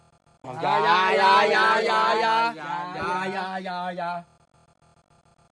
เสียง อย่าๆๆๆ มีม
หมวดหมู่: เสียงมีมไทย